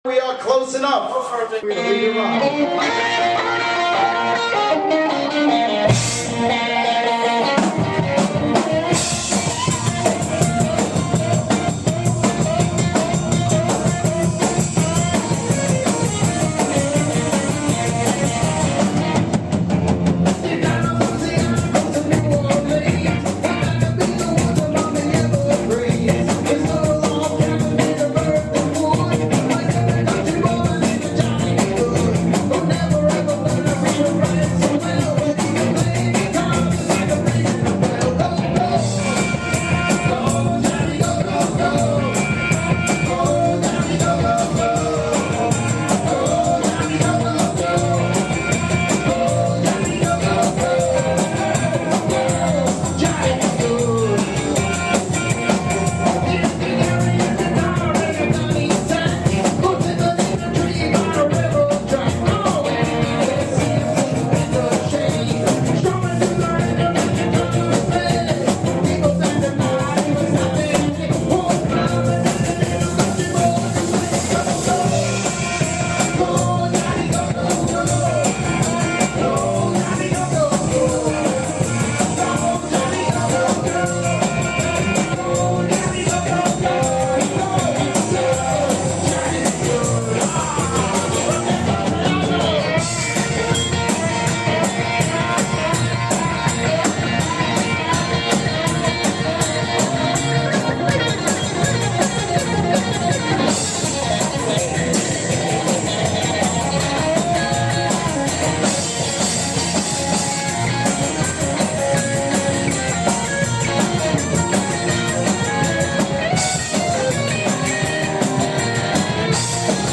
�The Best in Classic Rock?